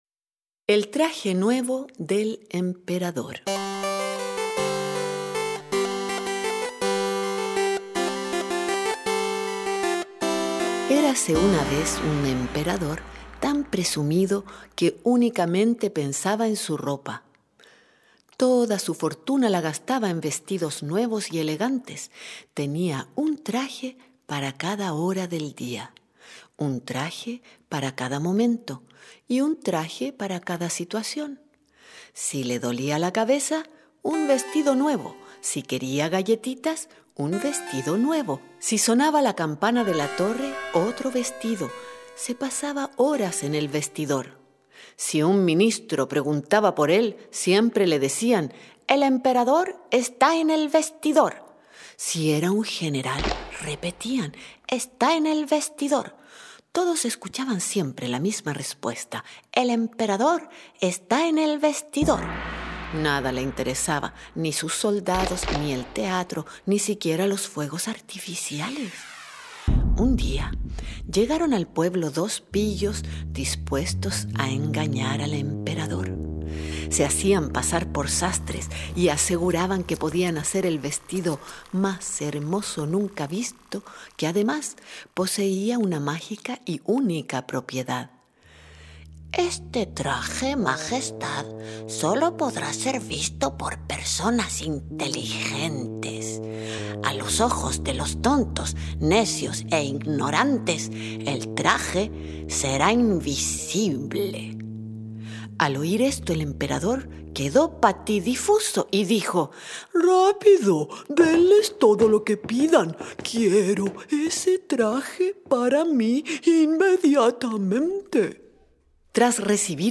Audiolibro: El traje nuevo del emperador
Cuento